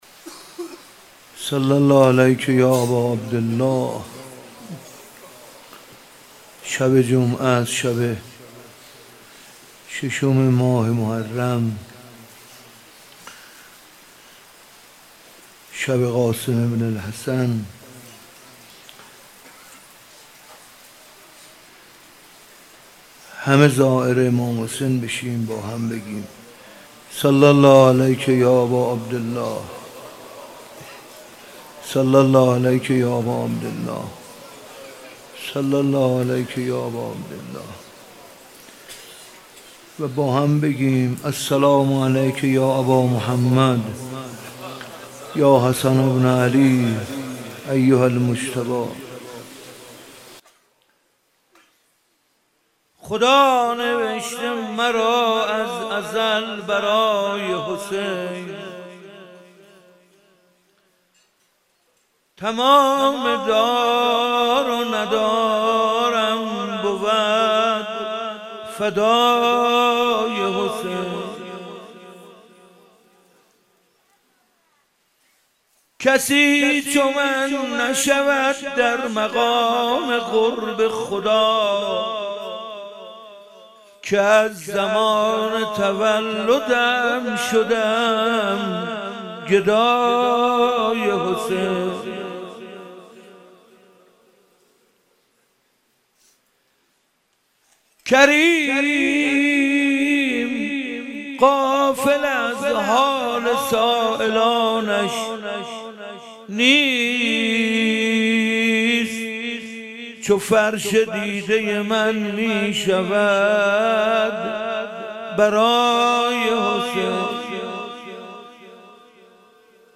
مداحی و روضه